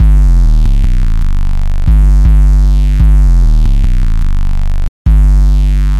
描述：3号嚎叫声来自中国冠毛犬Shaggy.